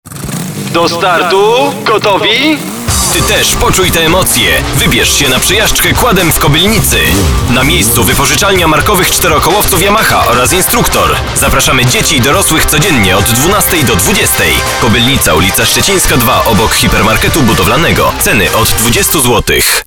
Professioneller polnischer Sprecher für TV / Rundfunk / Industrie.
Sprechprobe: eLearning (Muttersprache):
polish voice over artist